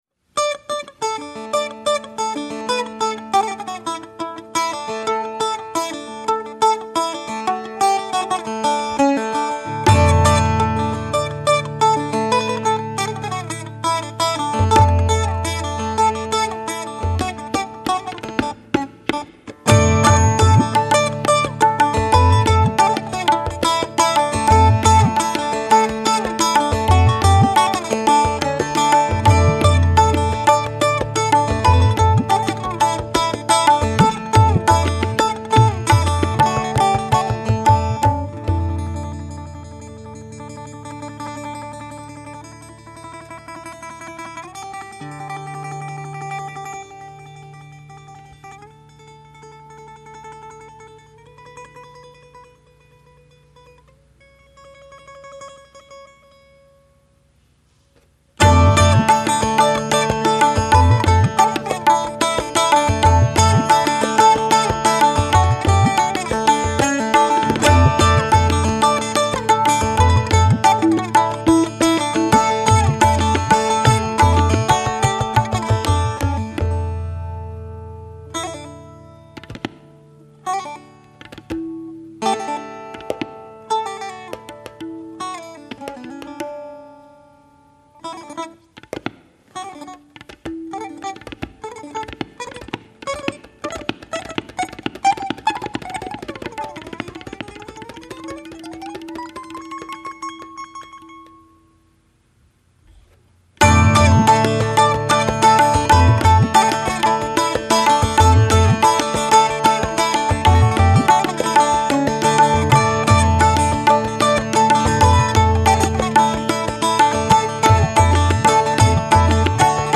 Cranberry Lake NY